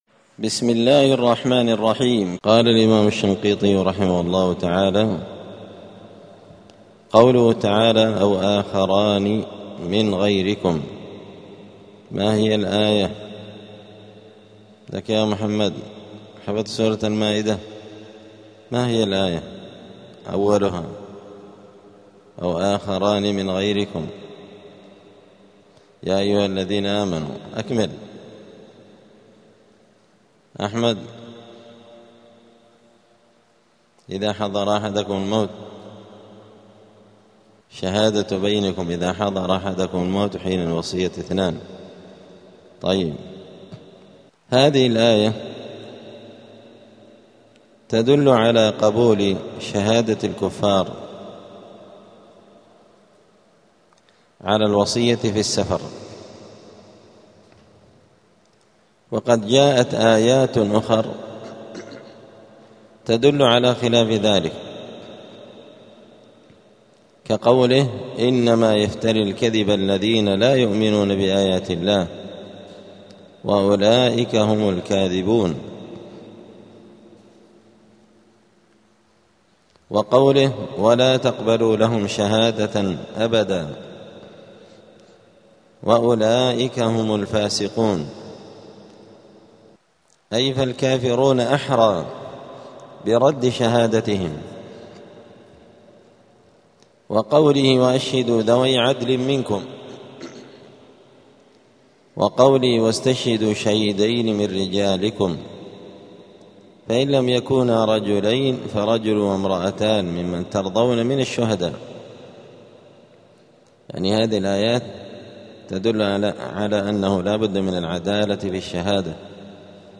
الجمعة 8 شوال 1447 هــــ | الدروس، دروس القران وعلومة، دفع إيهام الاضطراب عن آيات الكتاب | شارك بتعليقك | 4 المشاهدات